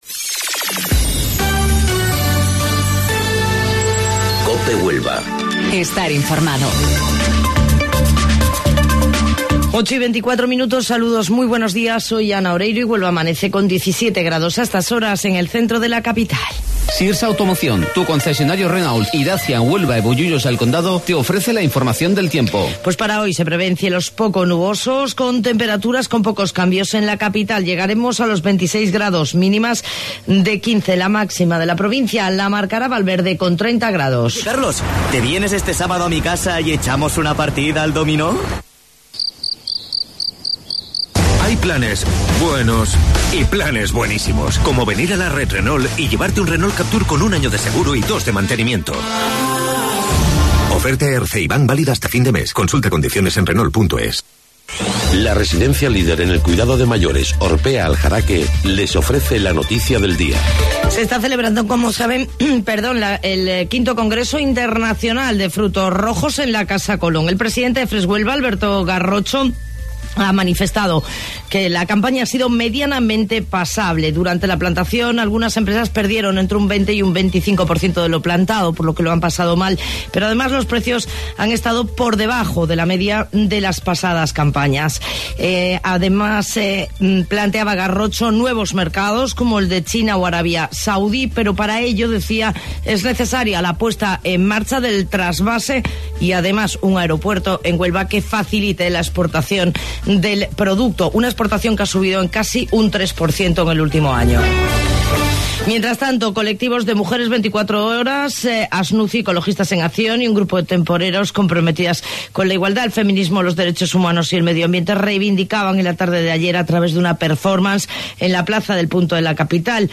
AUDIO: Informativo Local 08:25 del 20 de Junio